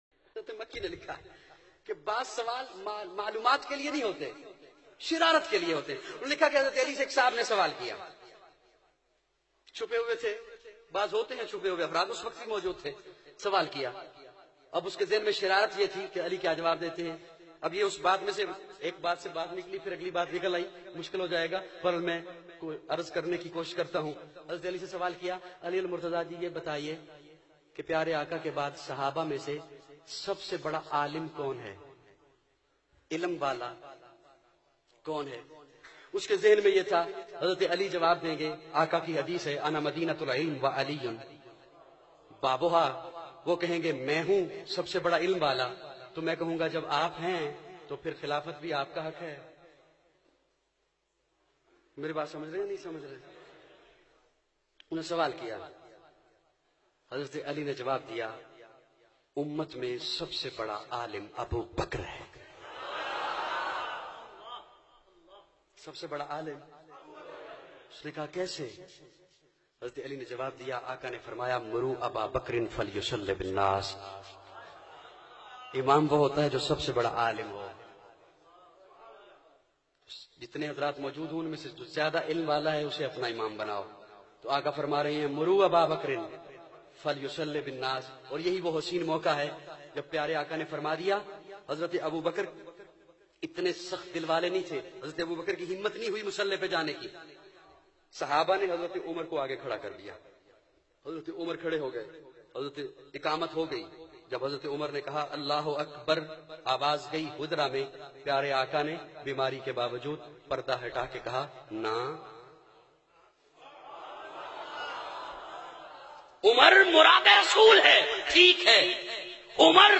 shan e ali bayan mp3